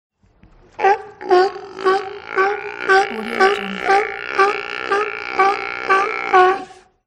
Sea Lion Efeito Sonoro: Soundboard Botão
Sea Lion Botão de Som